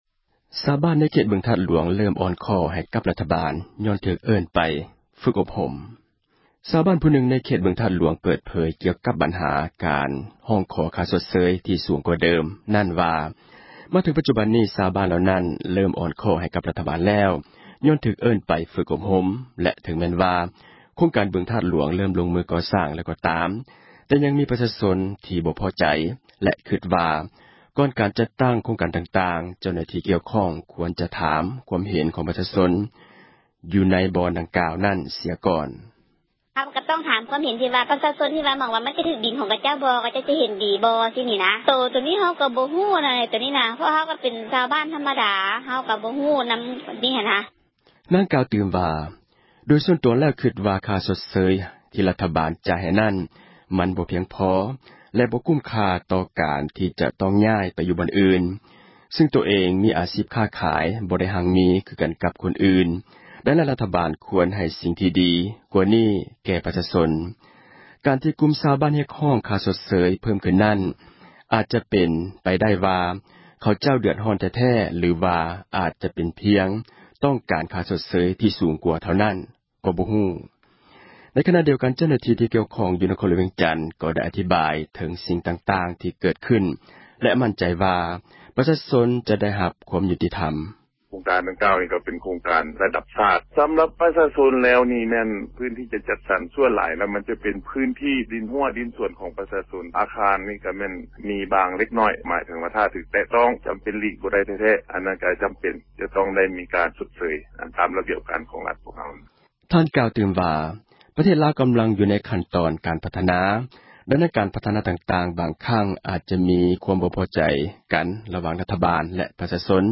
ຊາວບ້ານຜູ້ນຶ່ງ ໃນເຂດ ບຶງທາດຫລວງ ເປີດເຜີຍ ກ່ຽວກັບ ບັນຫາ ການຮ້ອງຂໍ ຄ່າຊົດເຊີຍ ທີ່ສູງກ່ວາເດີມ ນັ້ນວ່າ ມາເຖິງ ປັຈຈຸບັນນີ້ ຊາວບ້ານ ເຫລົ່ານັ້ນ ເຣີ້ມອ່ອນຂໍ້ ໃຫ້ກັບ ຣັຖບານແລ້ວ ຍ້ອນຖືກເອີ້ນໄປ ຝຶກອົບຮົມ ແລະ ເຖິງແມ່ນວ່າ ໂຄງການ ບຶງທາດຫລວງ ເຣີ້ມລົງ ມືກໍ່ສ້າງ ແລ້ວກໍຕາມ ແຕ່ຍັງມີປະຊາຊົນ ທີ່ບໍ່ພໍໃຈ ແລະ ຄຶດວ່າ ກ່ອນການຈັດຕັ້ງ ໂຄງການຕ່າງໆ, ເຈົ້າໜ້າທີ່ ກ່ຽວຂ້ອງ ຄວນຈະຖາມ ຄວາມເຫັນ ຂອງປະຊາຊົນ ຢູ່ໃນບ່ອນດັ່ງກ່າວນັ້ນ ເສັຍກ່ອນ: